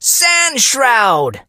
sandy_ulti_vo_01.ogg